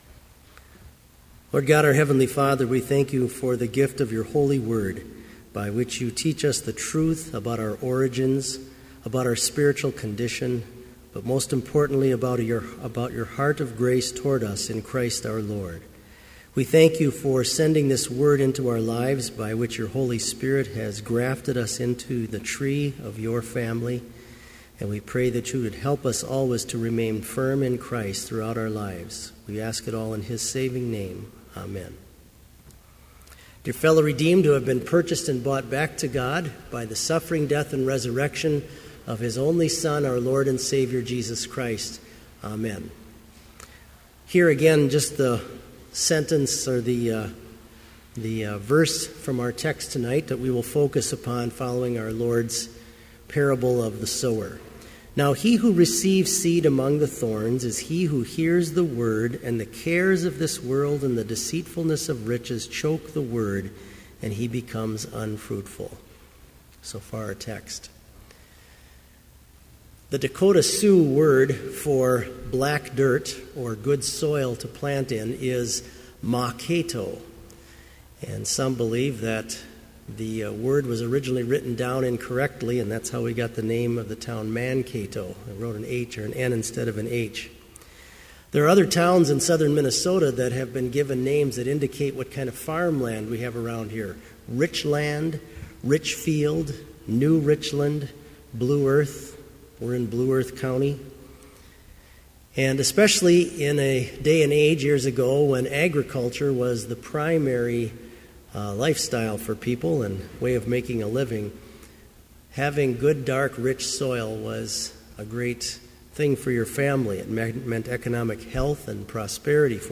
• Versicles & Gloria Patri (led by the choir)
(All may join with the choir to sing Hymn 69, vv. 1 & 8)
• Homily
(all may stand and join in singing:)
• Postlude